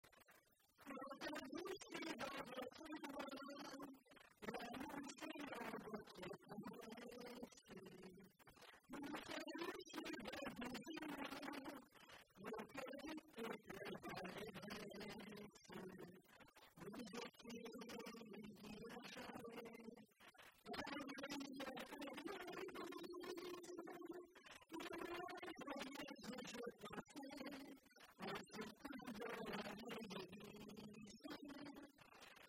circonstance : fiançaille, noce
Genre strophique
Pièce musicale inédite